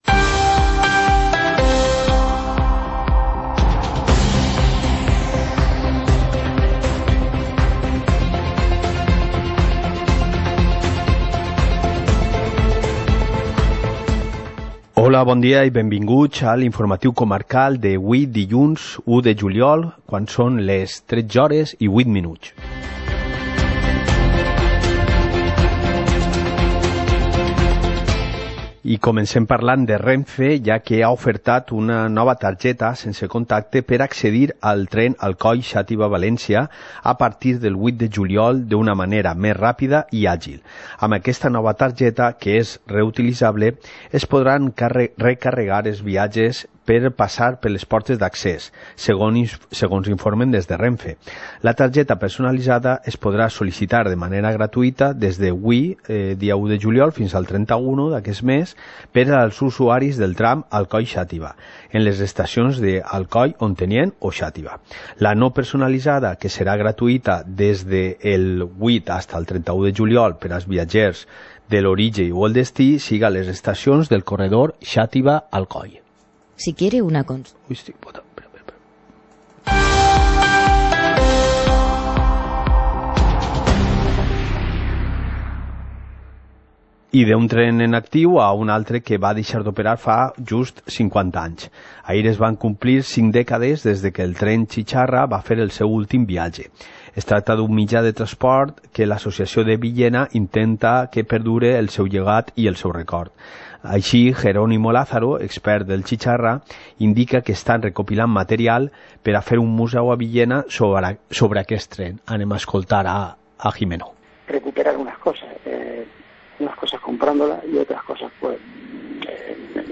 Informativo comarcal - lunes, 01 de julio de 2019